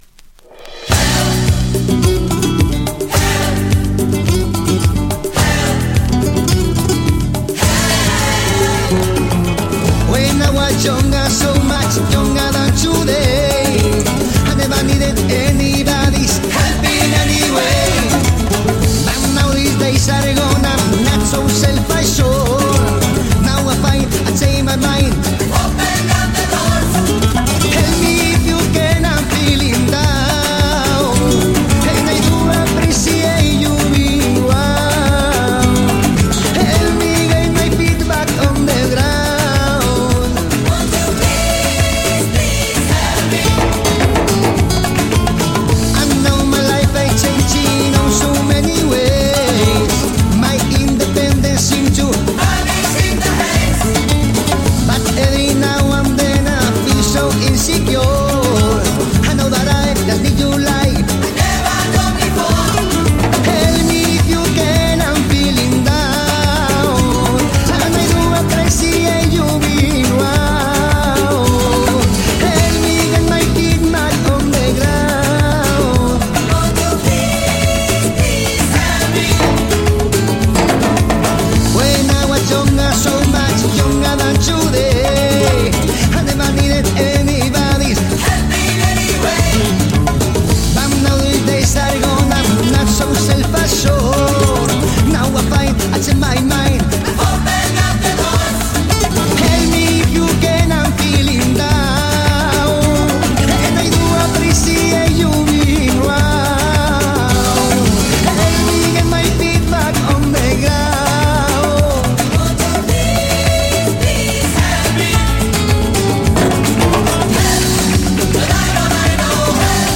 スペインの男女ポップ・グループ
お馴染みの名曲たちを、とびきり陽気なルンバ・ビートでカヴァーした全14曲、踊らずにはいられない強力盤です。
※曲間、静かな箇所でややチリ音が出ますが、曲中はあまり気になりませんでした。